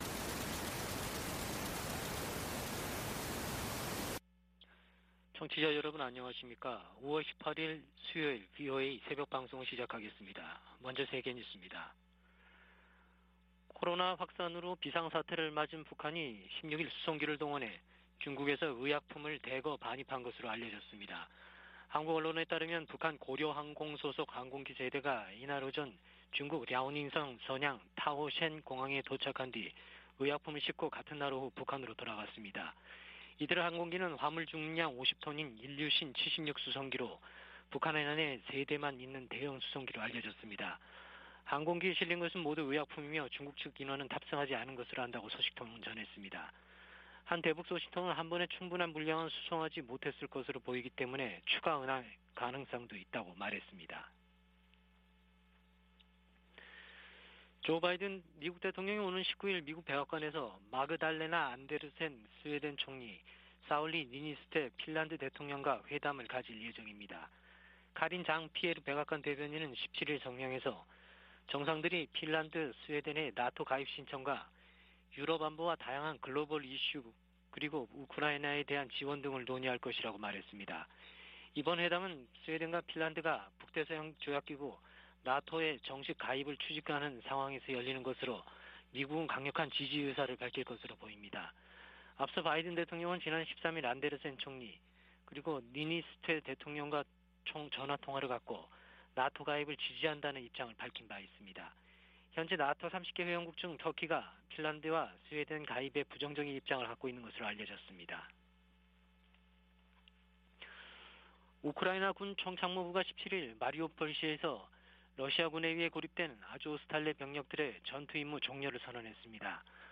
VOA 한국어 '출발 뉴스 쇼', 2022년 5월 18일 방송입니다. 북한은 연일 신종 코로나바이러스 감염증 발열자가 폭증하는 가운데 한국 정부의 방역 지원 제안에 답하지 않고 있습니다. 세계보건기구가 북한 내 급속한 코로나 확산 위험을 경고했습니다. 북한의 IT 기술자들이 신분을 숨긴 채 활동하며 거액의 외화를 벌어들이고 있다고 미국 정부가 지적했습니다.